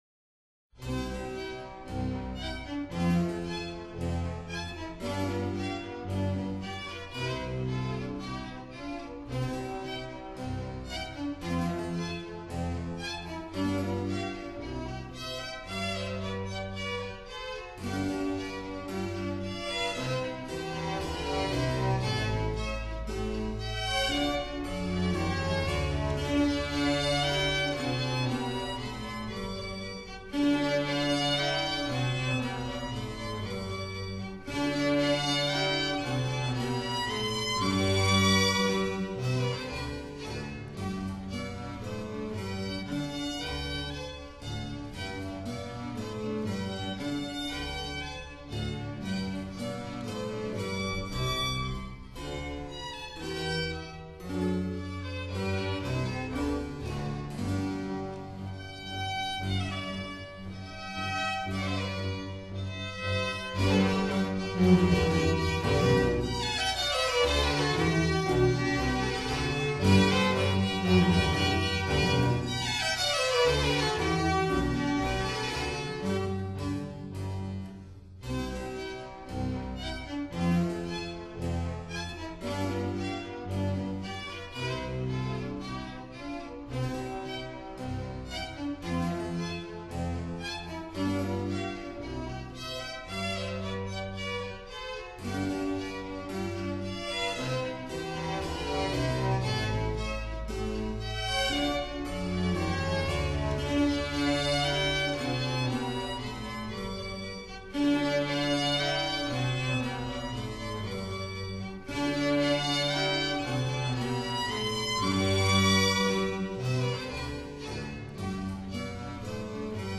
古典弦樂三重奏